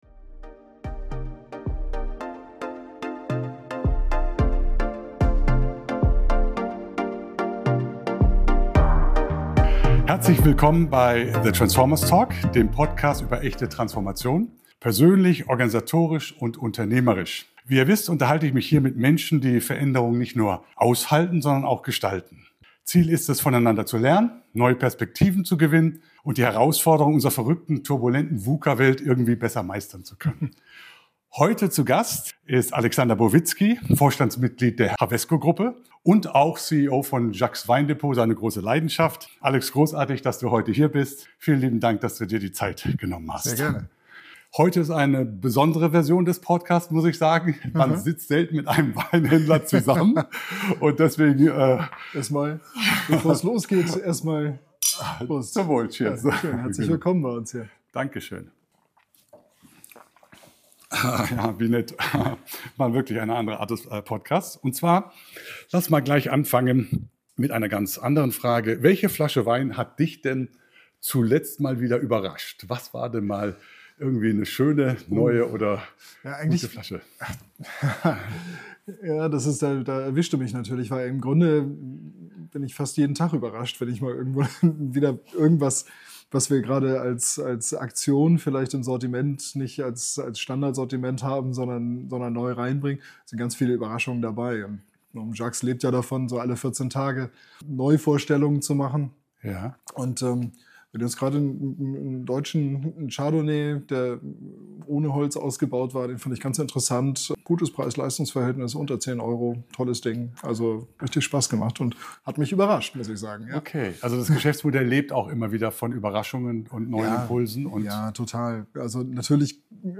Bei einem Glas Wein geht es um die Frage, wie Führung in herausfordernden Zeiten gelingt – ohne Schockstarre, aber auch ohne Aktionismus. Ein Gespräch über Mut, Gelassenheit und Verantwortung in der Transformation großer Organisationen.